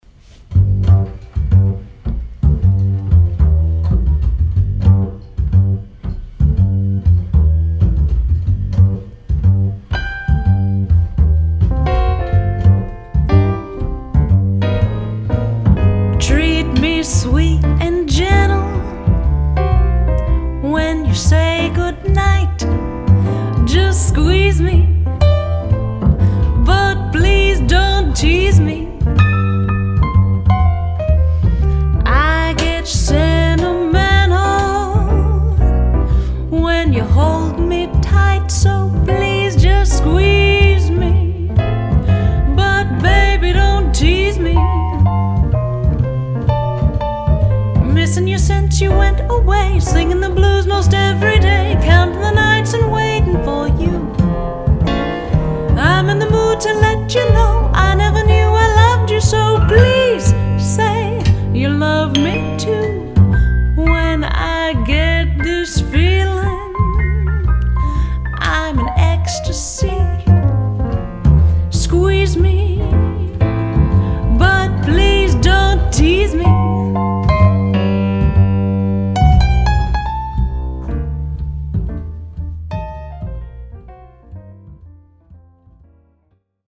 piano
bass.